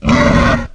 Soundscape Overhaul / gamedata / sounds / monsters / boar
boar_pain_0.ogg